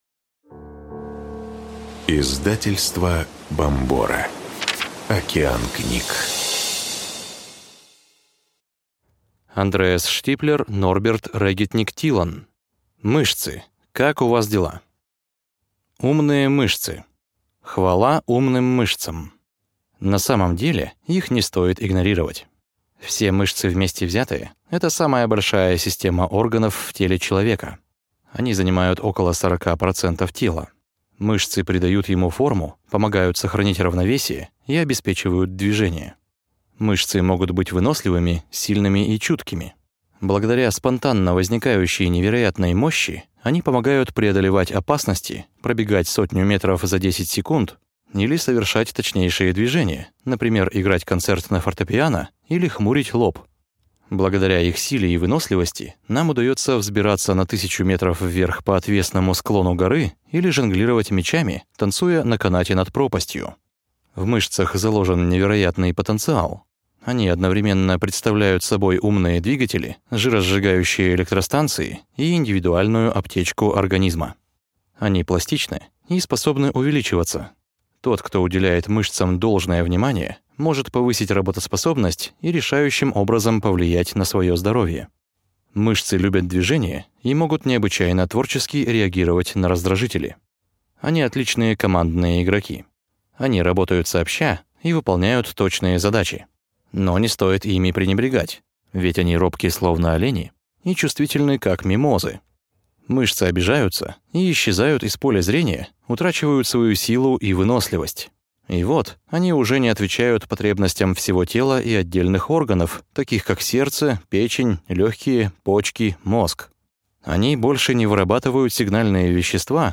Аудиокнига Мышцы. Как у вас дела? О том, как тренировка мышц укрепляет здоровье и омолаживает кожу | Библиотека аудиокниг